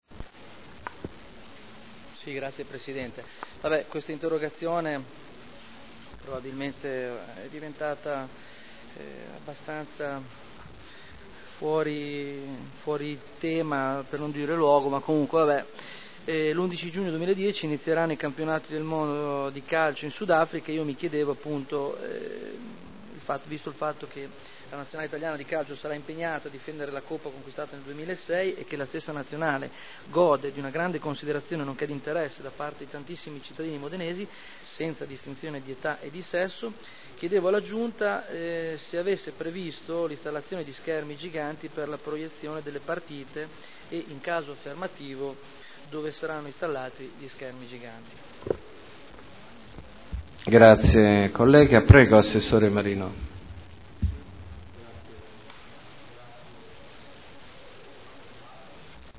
Seduta del 25/10/2010. Interrogazione del consigliere Barberini (Lega Nord) avente per oggetto: “Mondiali di calcio 2010”